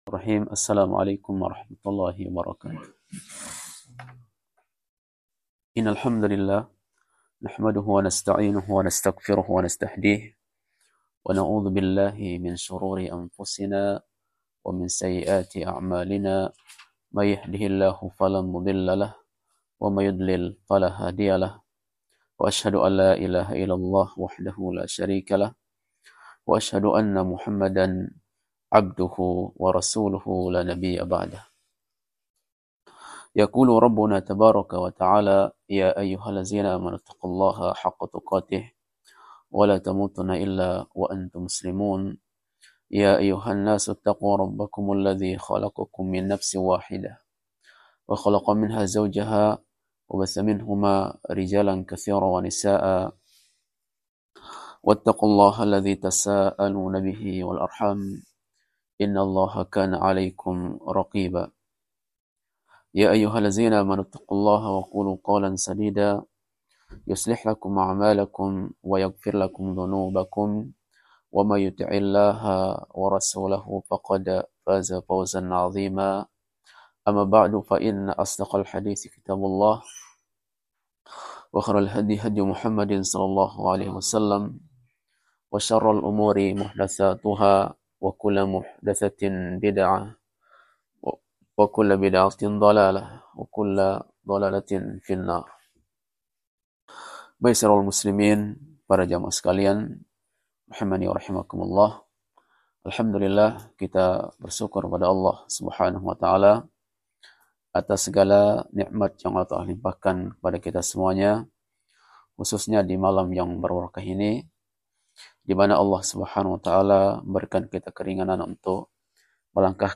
Kajian Ahad – Doha Membahas